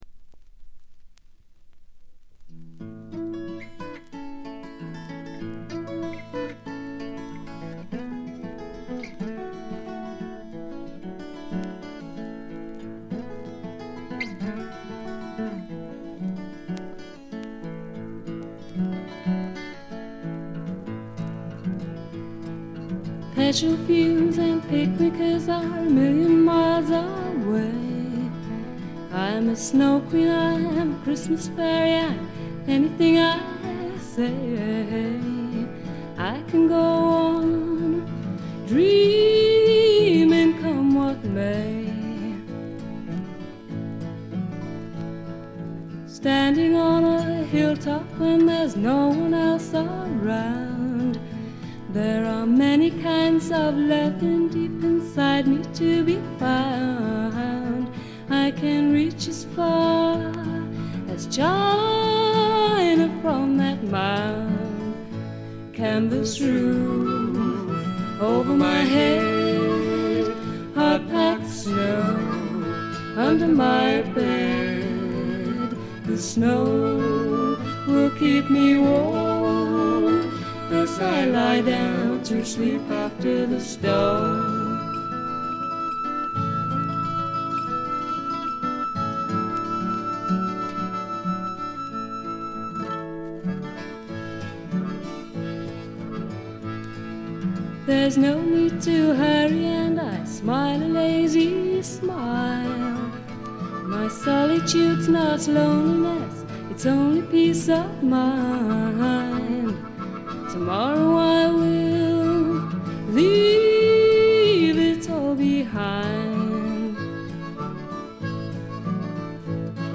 ところどころでチリプチやバックグラウンドノイズ。
試聴曲は現品からの取り込み音源です。